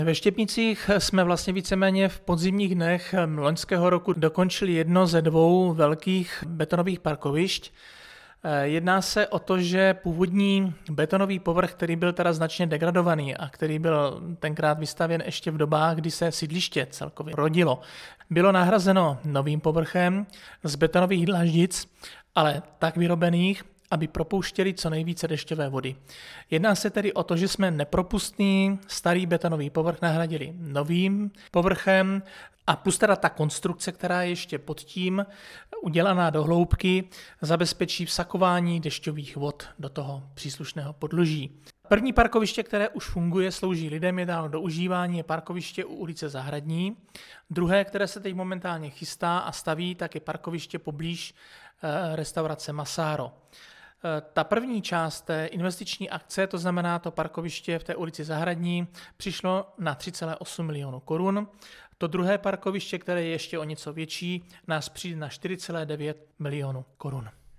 Foto: město Uherské Hradiště, rekonstrukce parkovací plochy - leden 2021 Pro média: audio 2020-02-09 Místostarosta Ing. Jaroslav Zatloukal - parkovací plochy Štěpnice MP3 | 1,43 MB